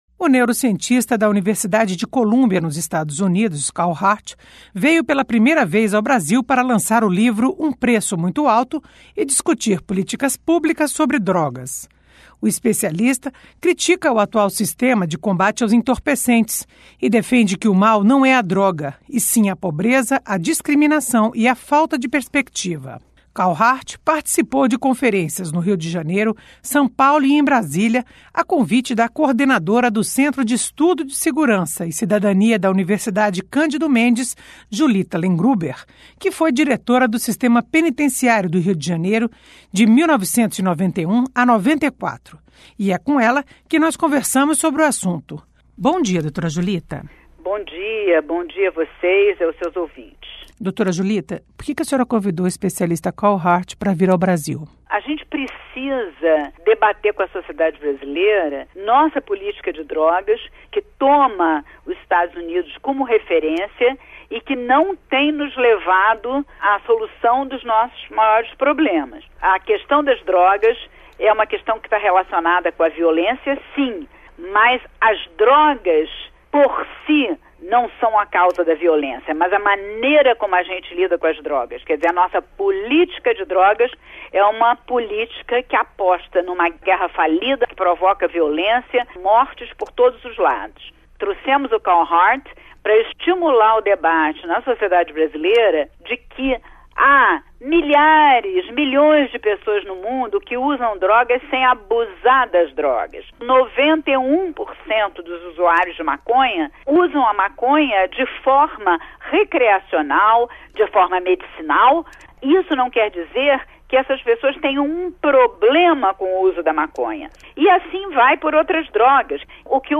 Entrevista: Políticas públicas de combate às drogas